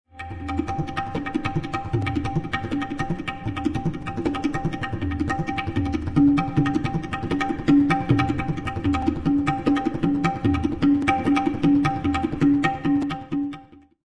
contemporary music piece